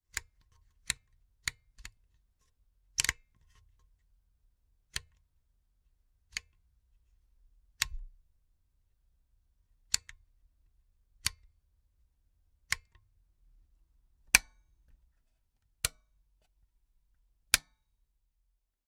Щелчки поворотного выключателя